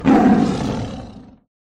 roar tiger - кнопка мгновенного звукового эффекта | Myinstants
roar tiger
roar-tiger.mp3